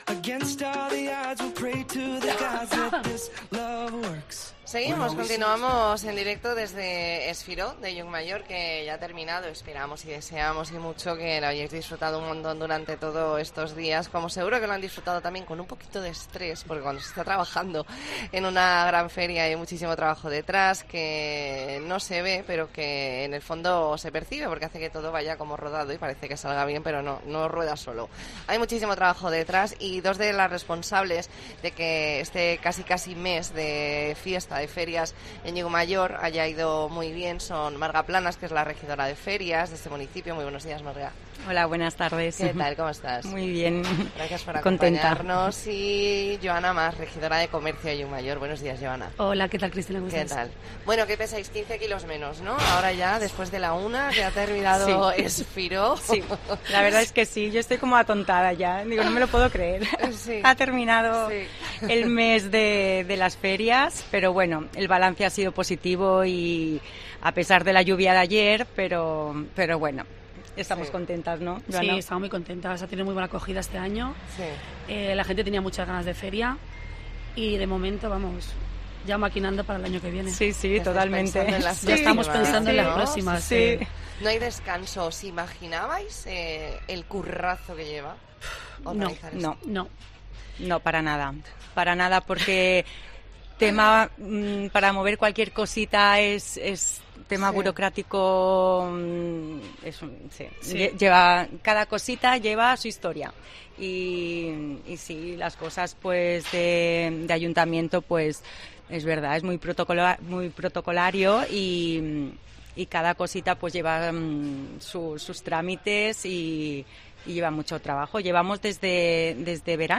AUDIO: Especial 'La Mañana en Baleares' desde Es Firó de Llucmajor
Redacción digital Madrid - Publicado el 16 oct 2023, 13:30 - Actualizado 16 oct 2023, 17:03 1 min lectura Descargar Facebook Twitter Whatsapp Telegram Enviar por email Copiar enlace Hablamos con Marga Planas, regidora de Ferias de Llucmajor y Joana Mas, regidora de Comercio de Llucmajor . Entrevista en La Mañana en COPE Más Mallorca, lunes 16 de octubre de 2023.